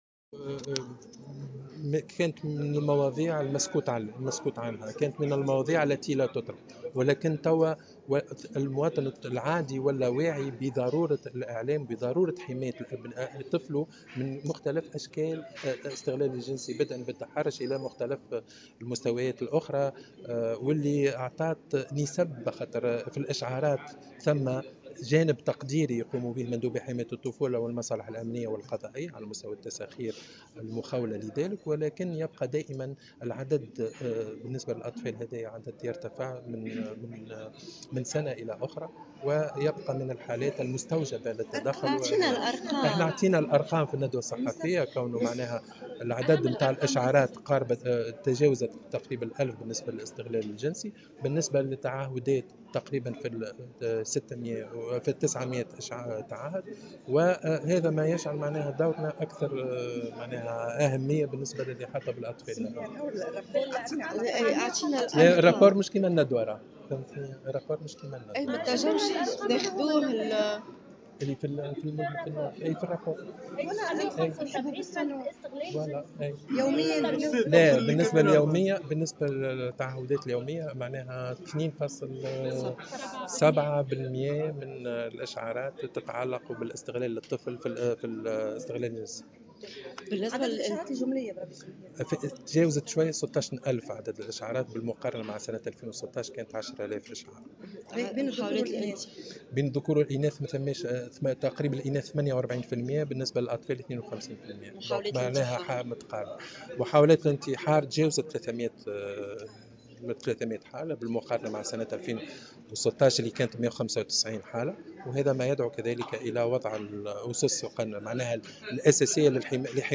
تلقى مندوبو حماية الطفولة خلال سنة 2017 حوالي 16.158 اشعارا شملت كل الفئات العمرية و خاصة الأطفال حديثي الولادة و اليافعين بين 13 و 15 سنة وفق ما أكده مندوب عام الطفولة مهيار حمادي في تصريح لمراسلة الجوهرة "اف ام".